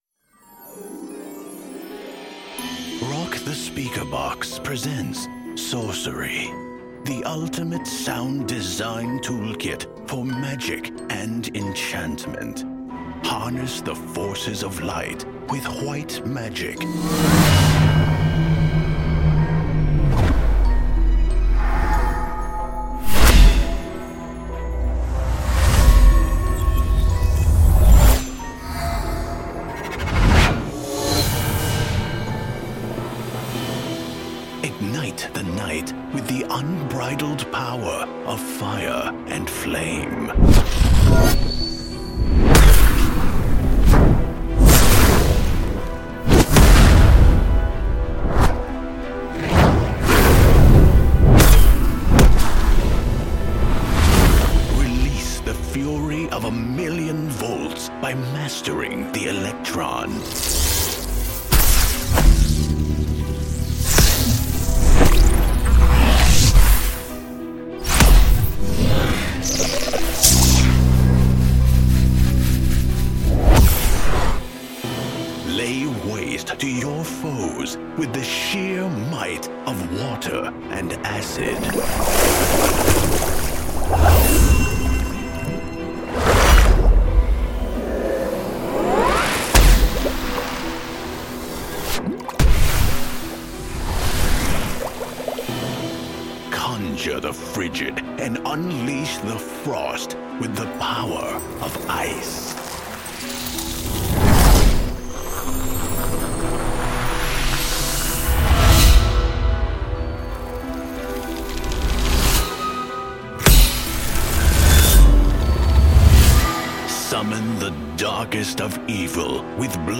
音效素材-邪恶黑暗巫师魔法终极冰火能量无损音效2196组
这套音效资源构建于六类物理能量声景：光波高频振荡、可燃物质裂变声场、次声波共振体系、等离子电弧瞬态、流体侵蚀声纹及冰晶结构声学特征。